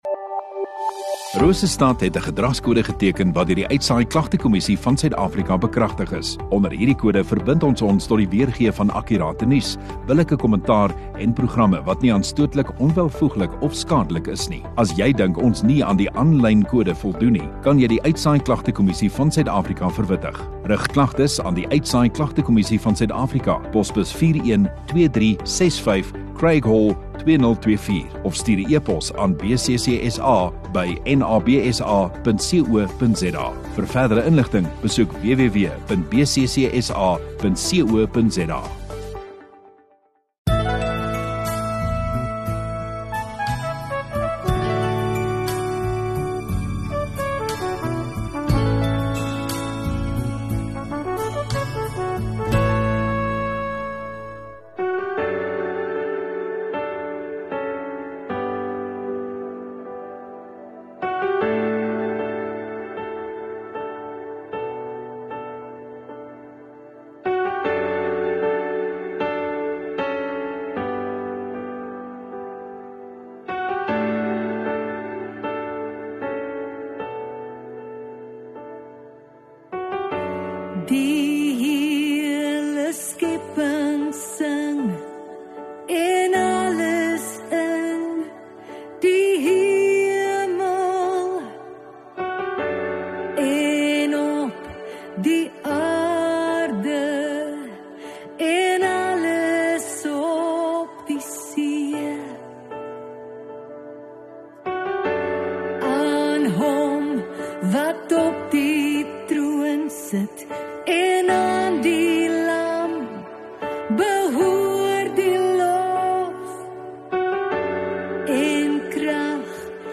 Rosestad Godsdiens 26 Jan Sondagoggend Erediens